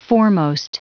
Prononciation du mot foremost en anglais (fichier audio)
Prononciation du mot : foremost